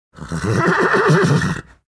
the-sound-of-whinnying-horse